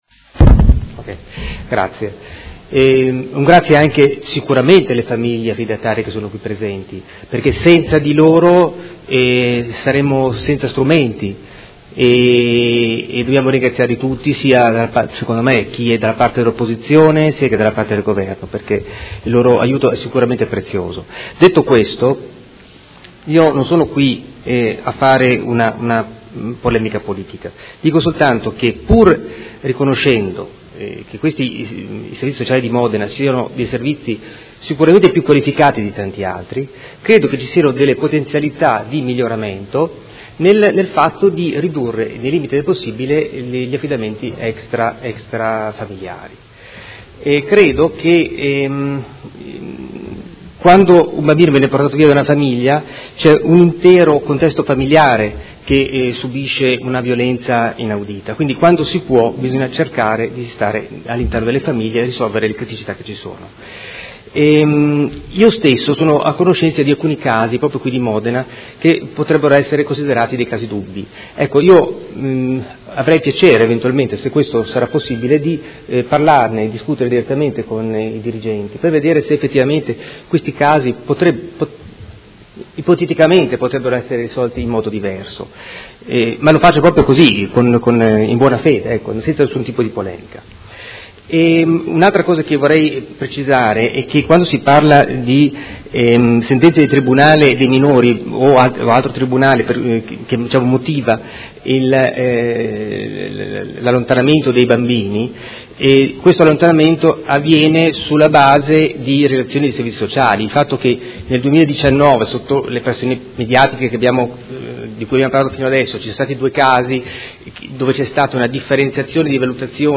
Seduta del 26/09/2019.